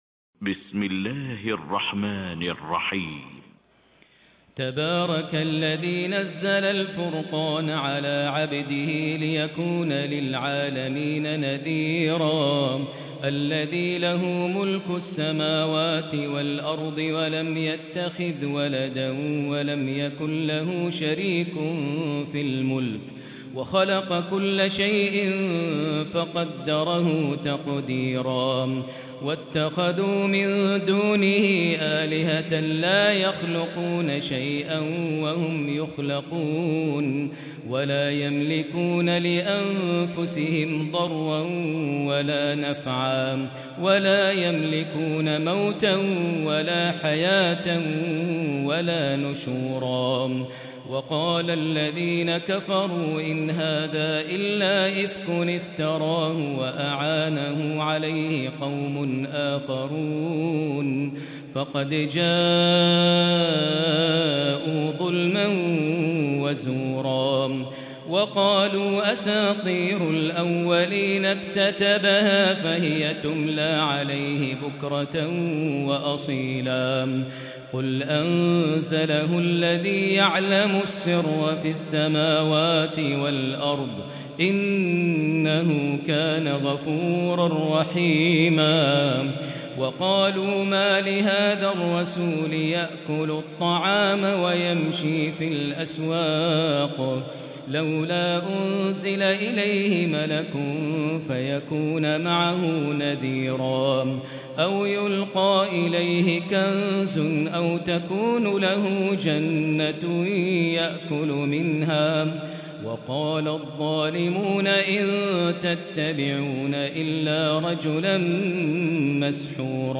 Tarawih prayer from the holy Mosque